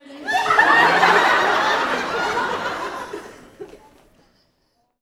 Audience Laughing-00.wav